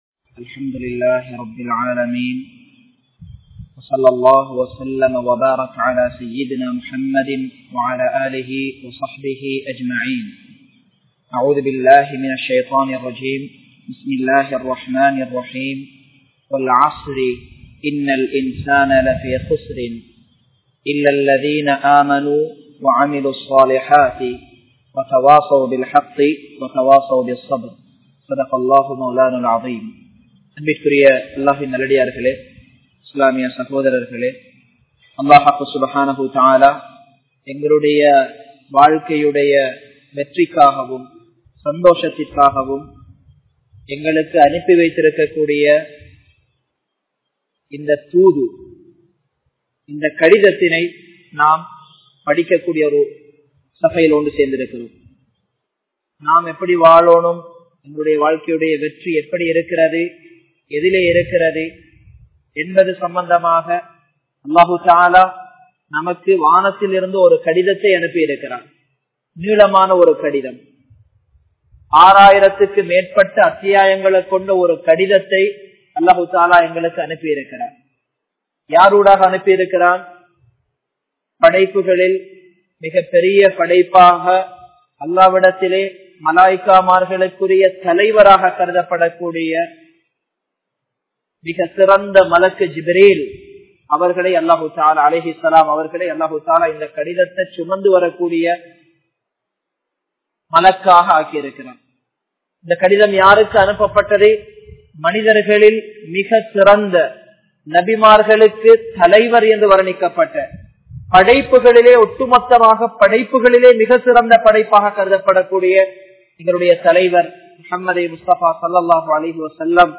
Importance Of Time | Audio Bayans | All Ceylon Muslim Youth Community | Addalaichenai
Muhideen (Markaz) Jumua Masjith